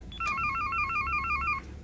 Sounds of the intercom to download and listen online
• Category: Intercom